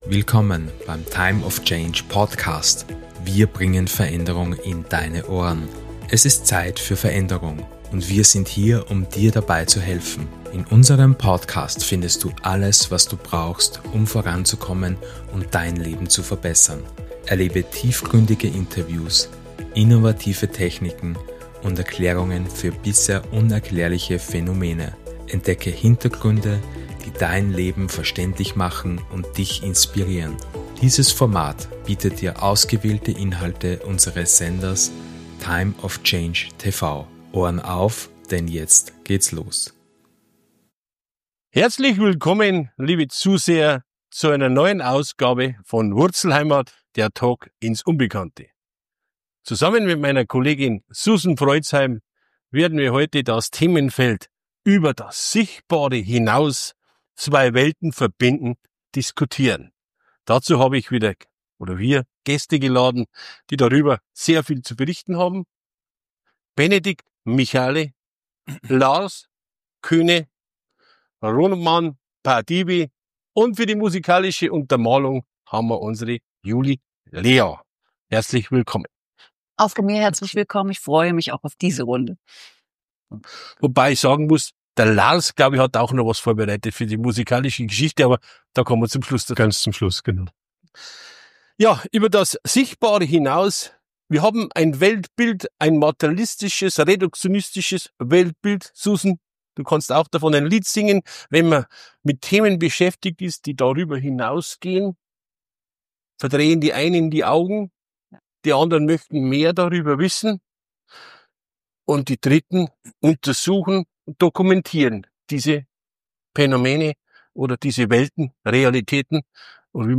WurzlHeimat - Der Talk ins Unbekannte